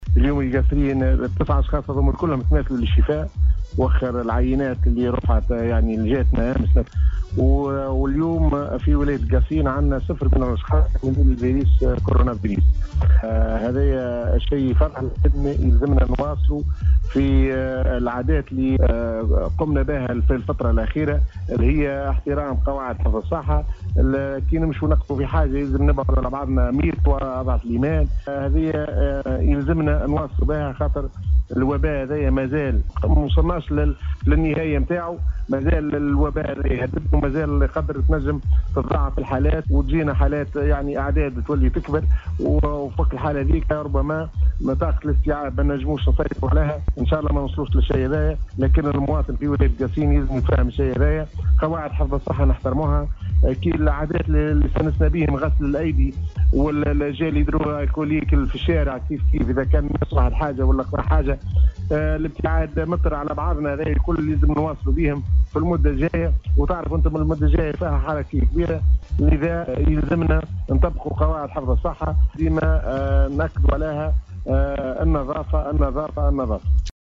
اكد المدير الجهوي للصحة بالقصرين الدكتور عبد الغني الشعباني  خلال مداخلته صباح اليوم في برنامج رمضان القصرين عبر موجة سيليوم اف ام ،  شفاء جميع الحالات بولاية القصرين من فيروس كورونا لتعود بذلك الجهة الى 0 اصابة .